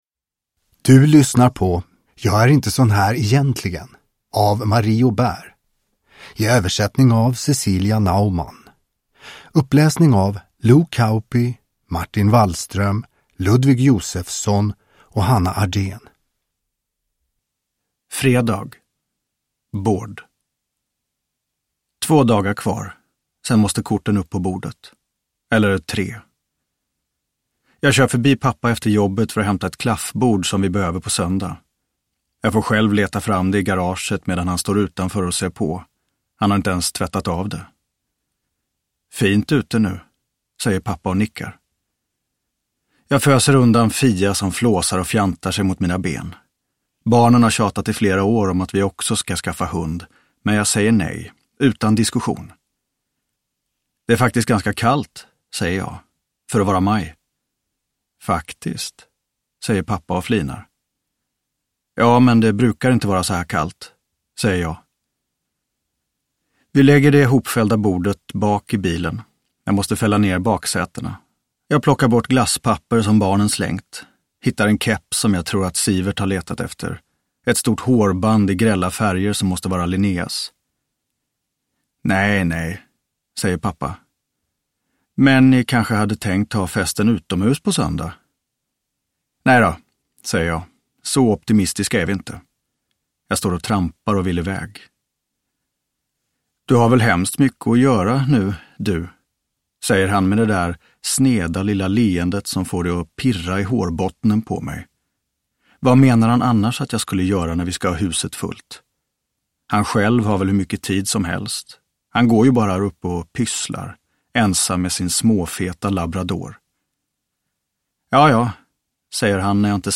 Jag är inte sådan här egentligen – Ljudbok – Laddas ner